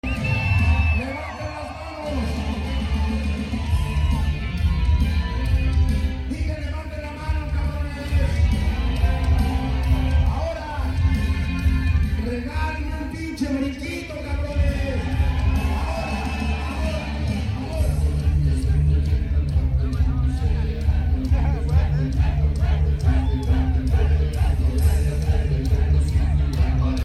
en Leonardo’s de Huntington park Los Angeles c.a.